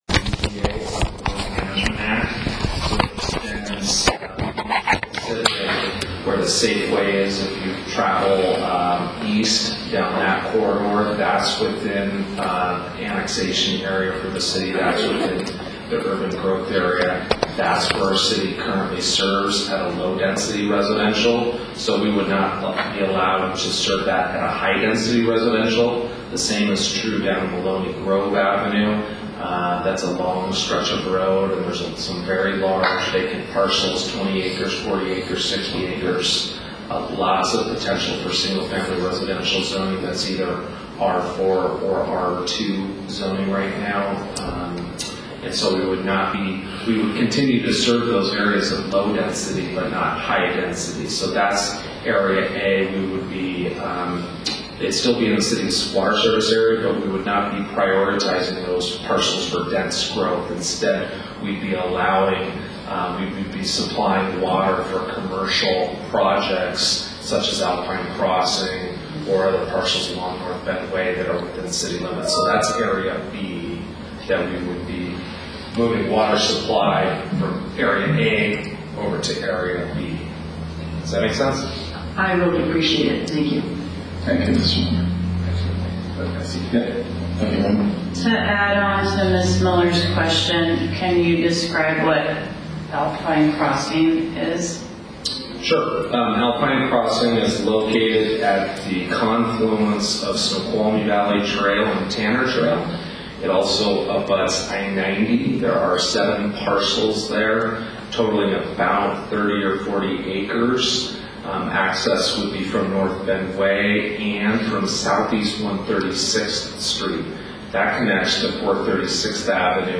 Meeting Audio - February 7, 2023 Part 2